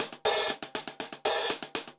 Index of /musicradar/ultimate-hihat-samples/120bpm
UHH_AcoustiHatC_120-01.wav